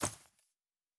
Coin and Purse 03.wav